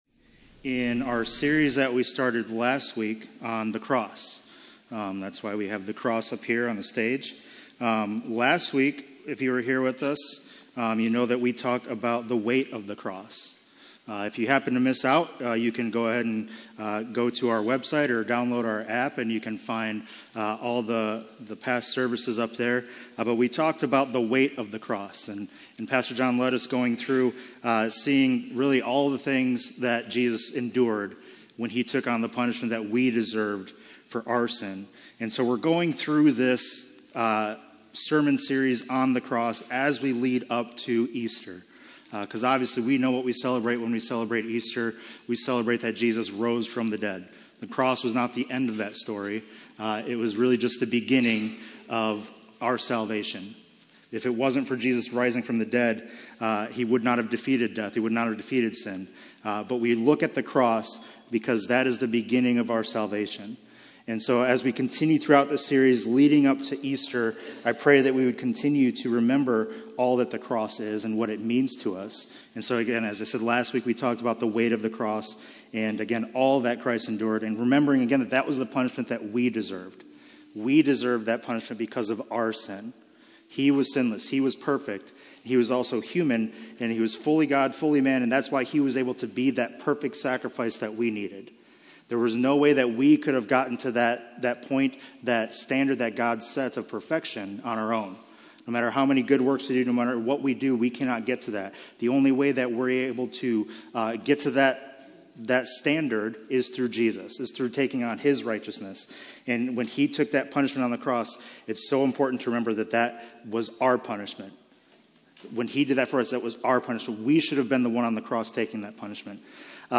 Passage: Romans 10:9-15 Service Type: Sunday Morning